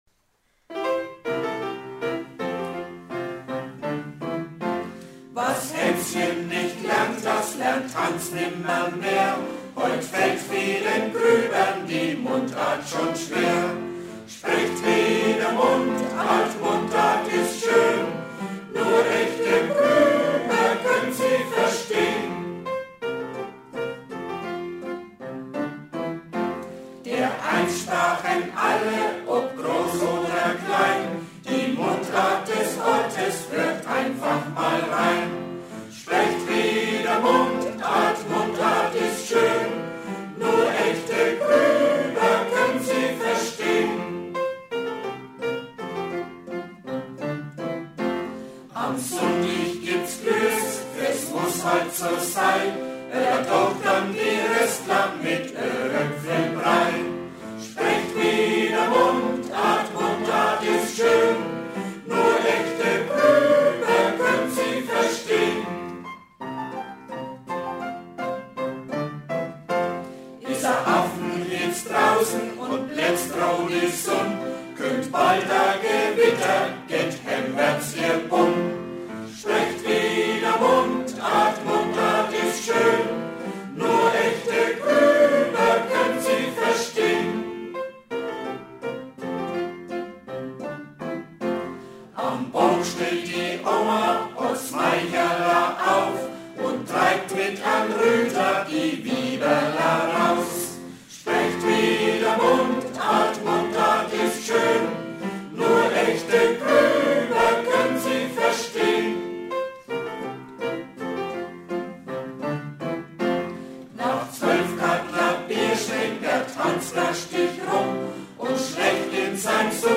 Ein Schnadahüpferl zur Mundart von Harald Ostrow gesungen von den Reichenbachsängern können Sie sich
Mundartlied.mp3